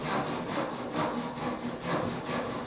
sawing-wood.ogg